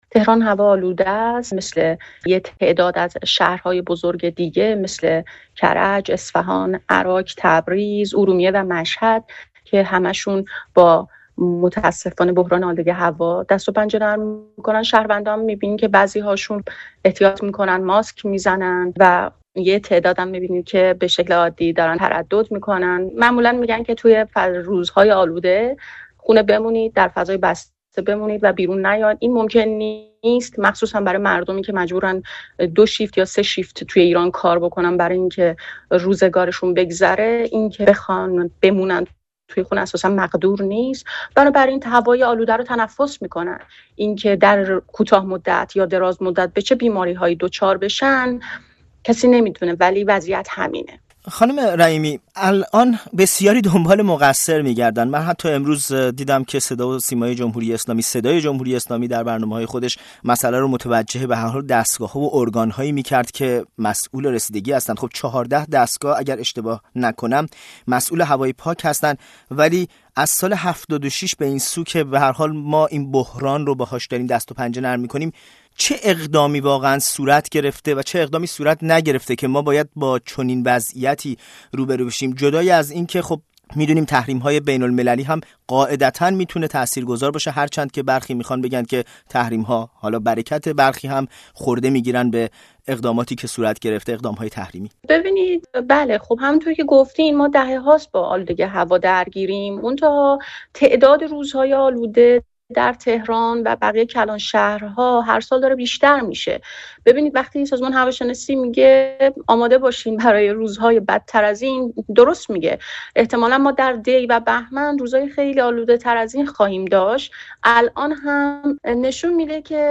در گفت‌وگویی با برنامه خبری-تحلیلی ساعت ۱۴ رادیوفردا